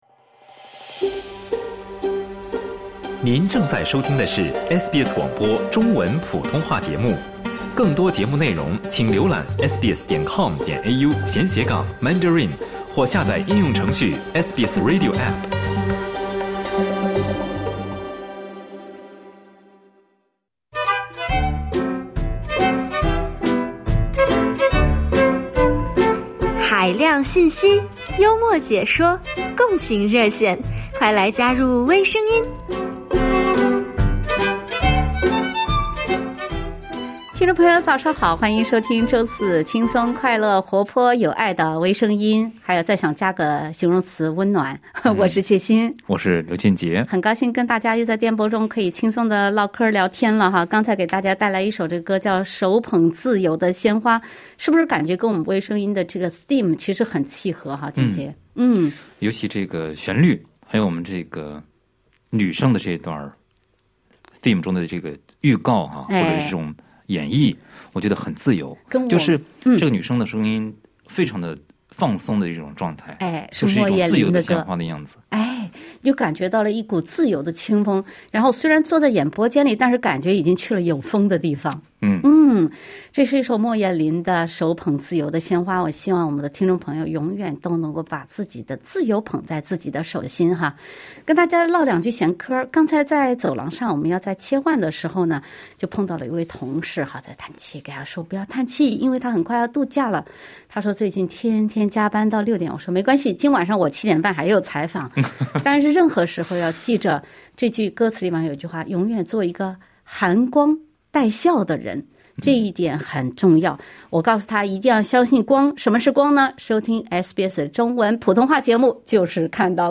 传说中的“长大后，我就成了你“系列。”热心听众分享自己的“相亲态度”。